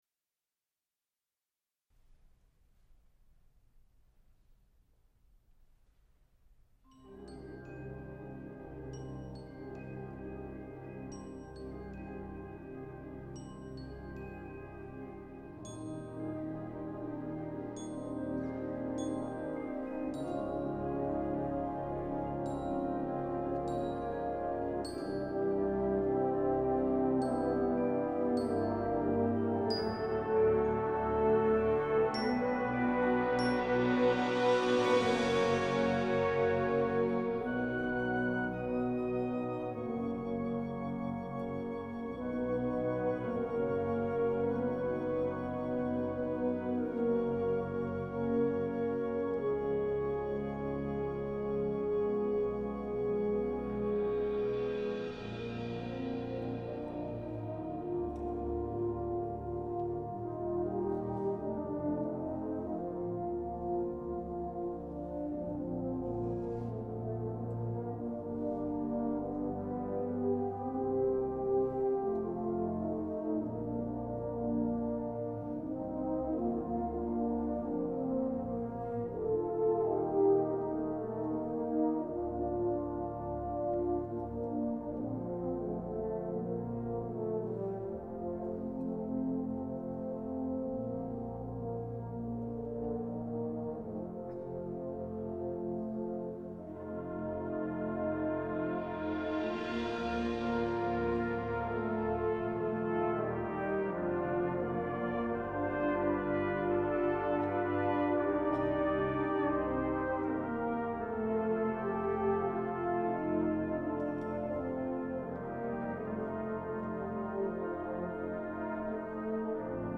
Genre: Band